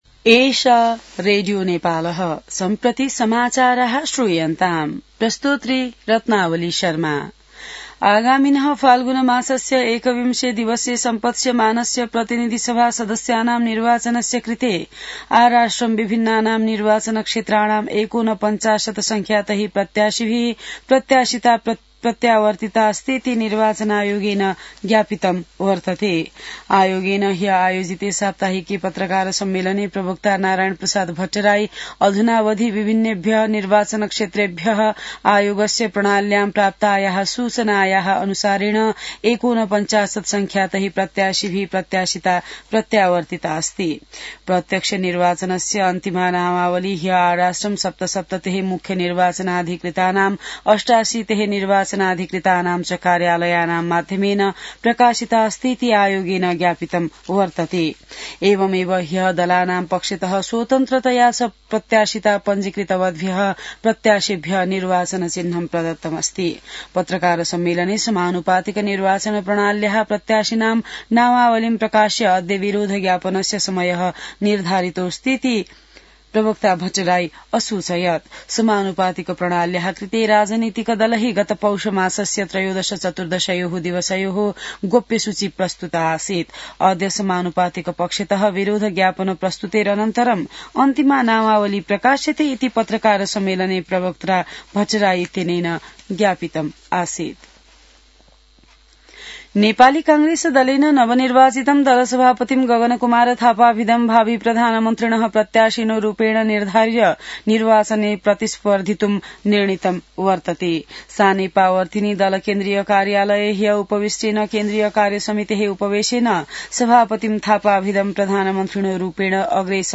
An online outlet of Nepal's national radio broadcaster
संस्कृत समाचार : १० माघ , २०८२